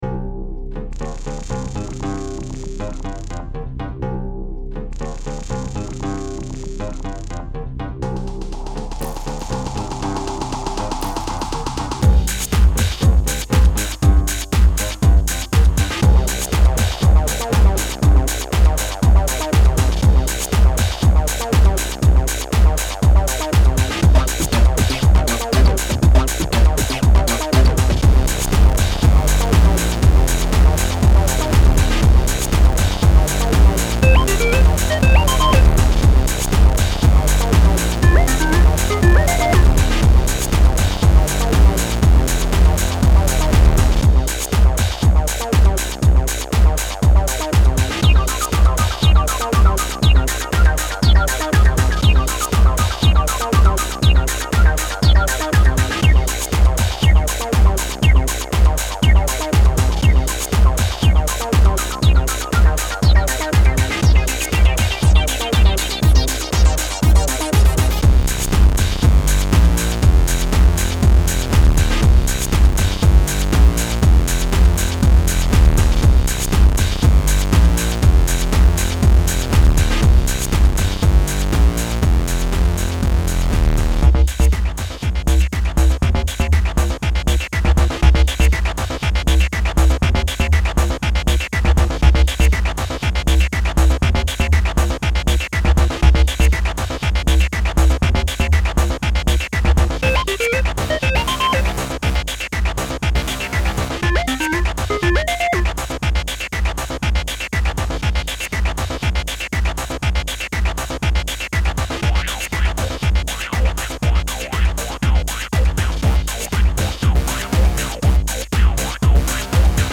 Apple's Final Cut Pro 4 comes with a tool called Soundtrack that lets you make royalty-free music -- very easily.